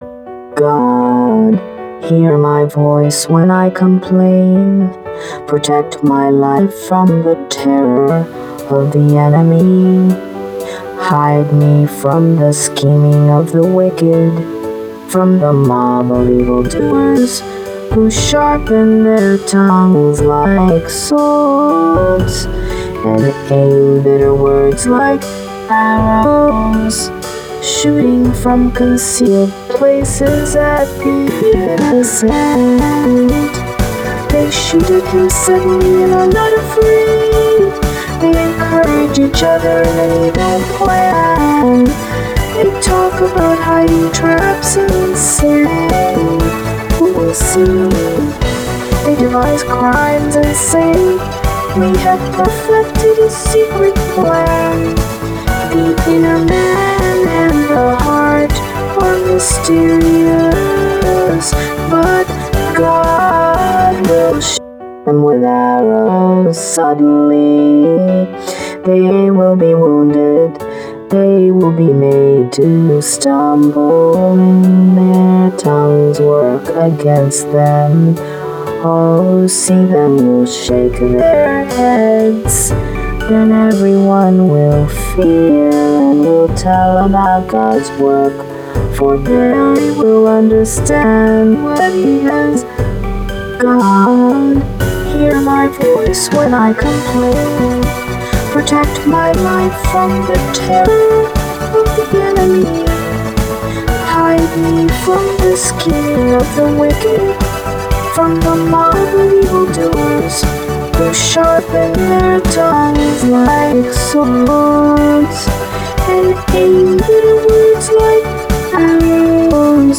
Autotune Poetry: Psalm 64